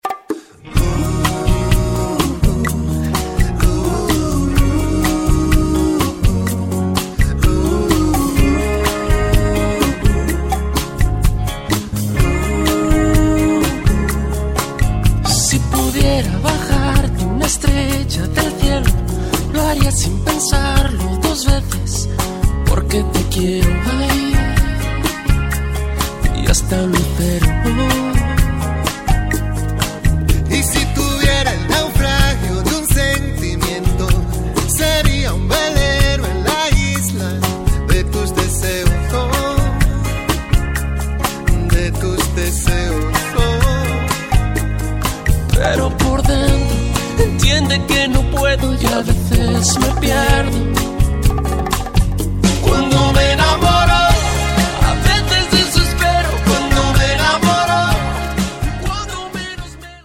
(20 Latin 3-Step, Rumba & Slow Dance Favorites)<